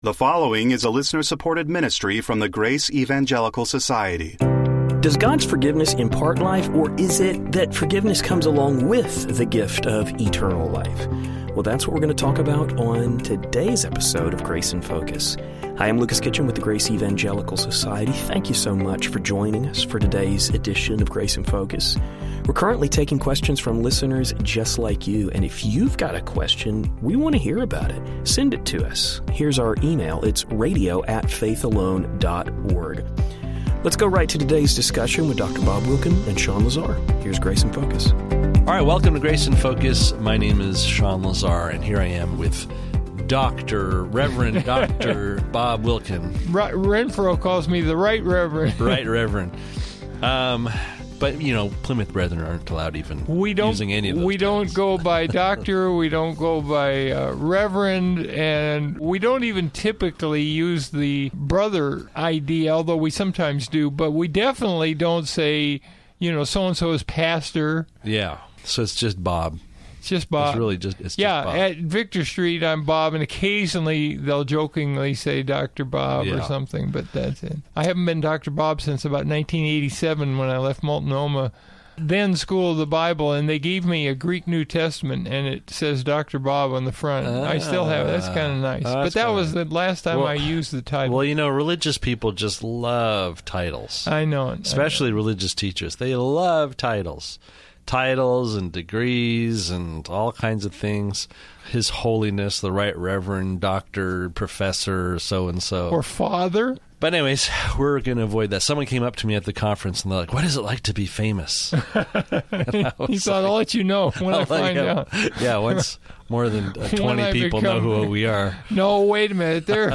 We will hear the guys address the role of forgiveness and its benefits on the show.